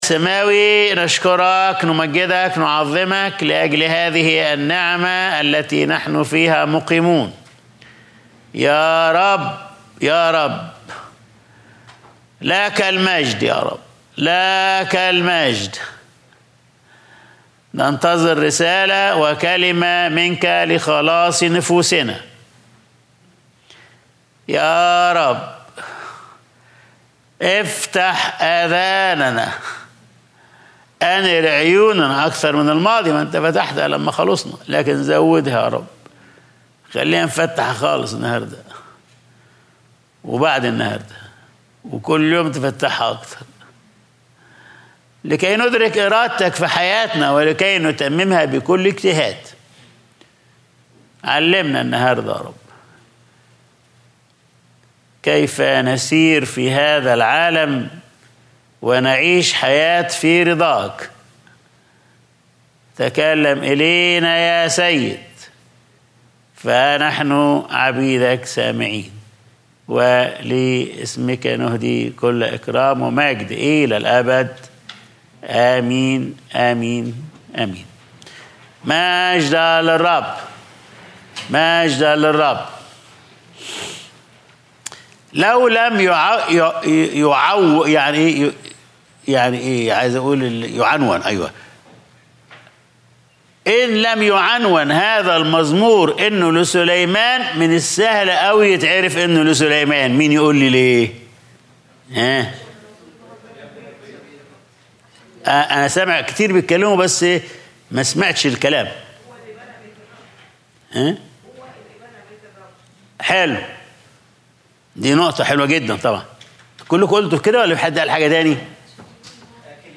Sunday Service | يُكلِّمون الأعداء في الباب